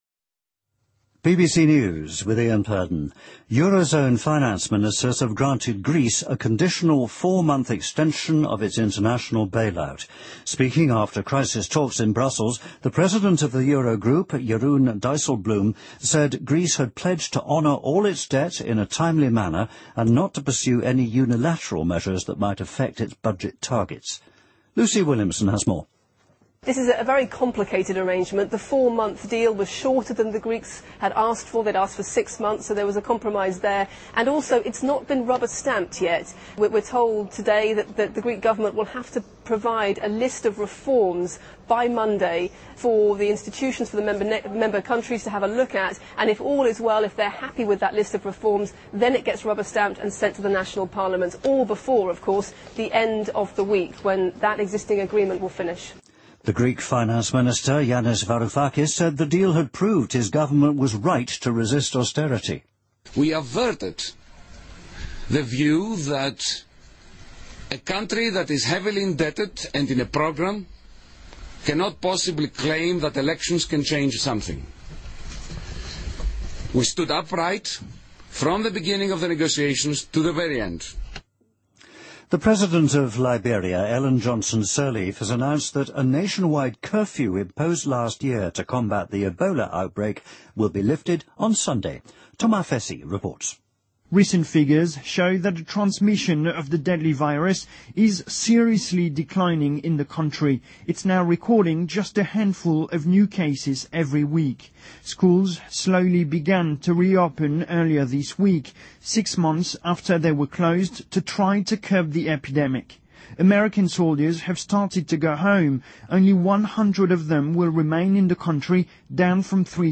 BBC news:2015-02-21����|BBC��������